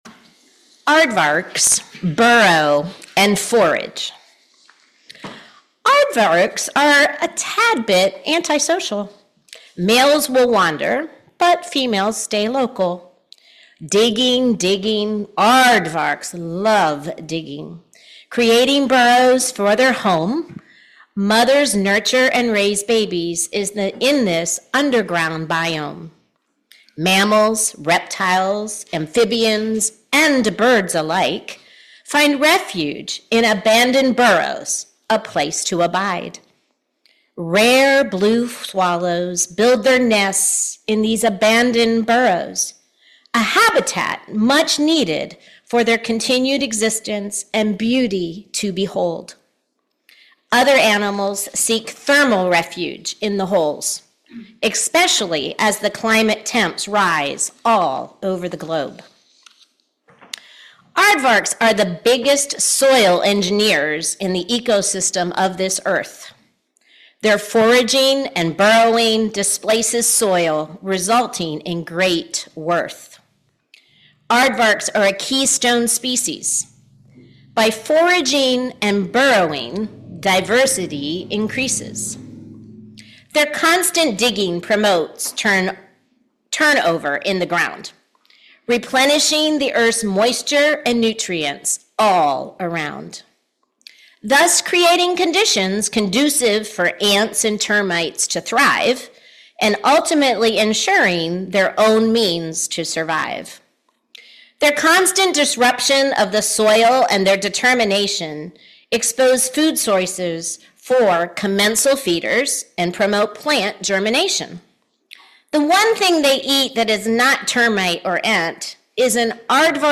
Speaker: UUCL Worship Committee